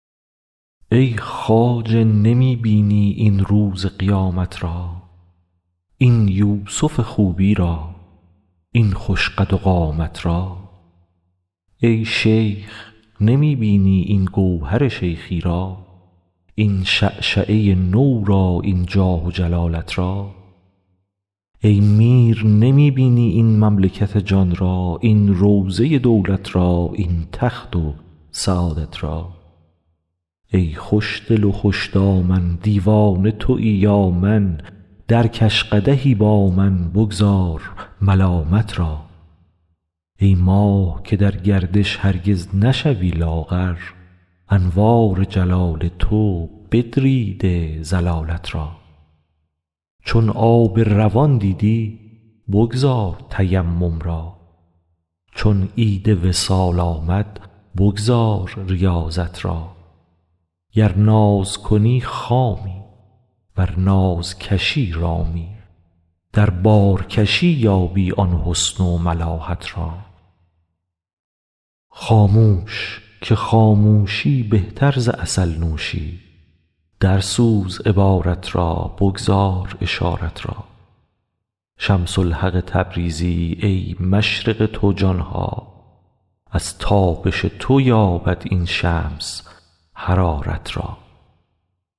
مولانا دیوان شمس » غزلیات غزل شمارهٔ ۷۵ به خوانش